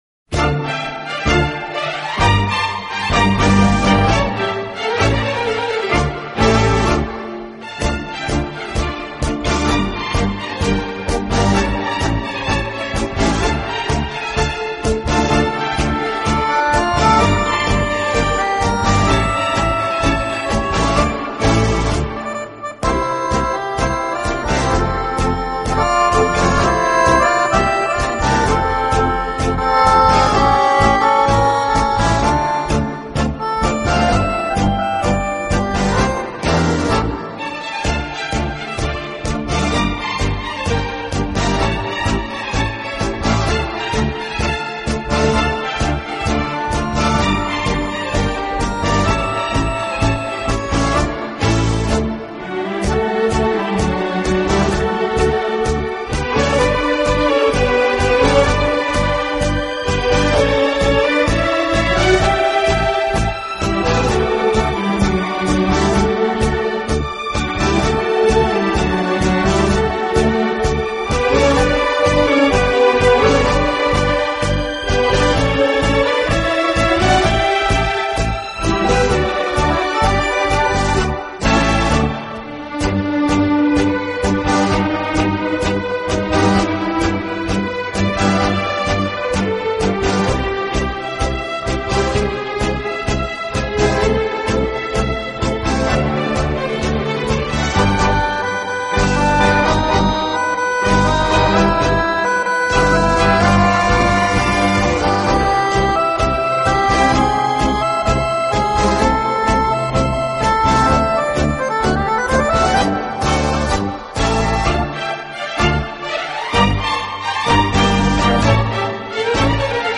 【轻音乐】
轻快、柔和、优美，带有浓郁的爵士风味。
德国轻音乐队。